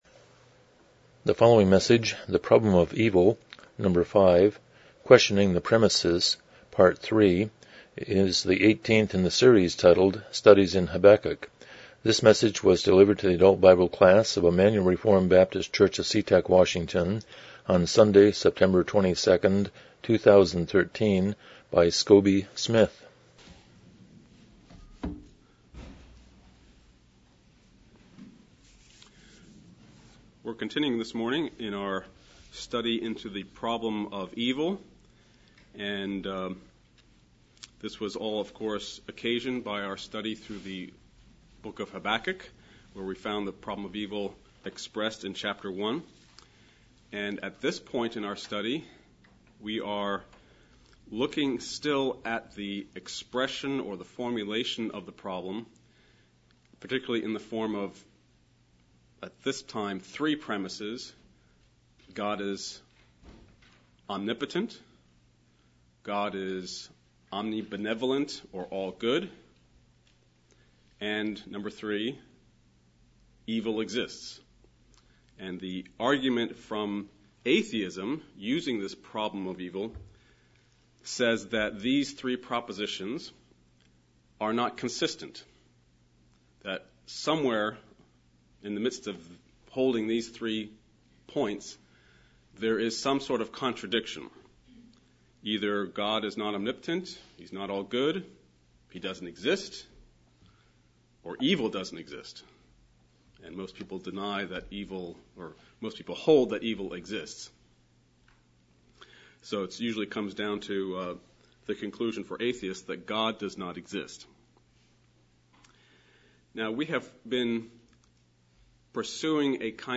Studies in Habakkuk Service Type: Sunday School « 5 Who is God? 1 38 The Sermon on the Mount